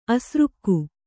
♪ asřkku